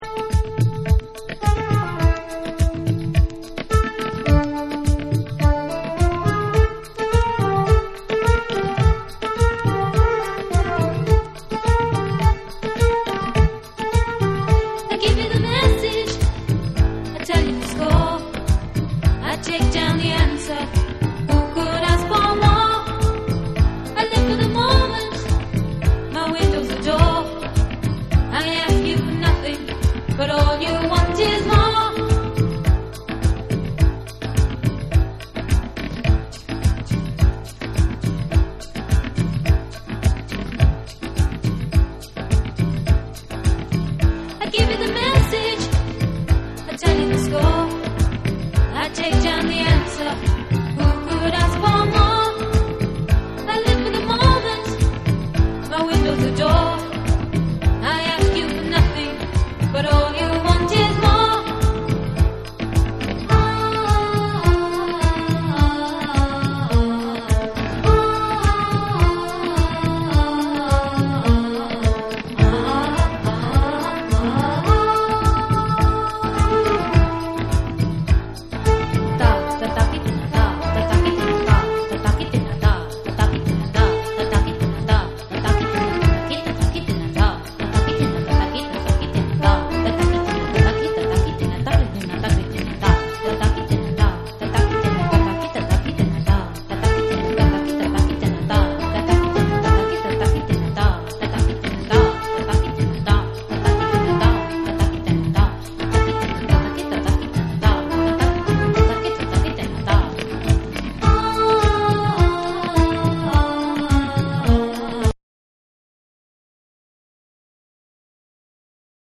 タブラやシタールといったインド古典楽器を用いた土着的かつアーバンなエキゾ・ダンサー
WORLD / ORGANIC GROOVE